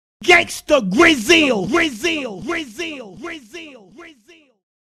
DJ Drama Gangsta Grillz TAG.mp3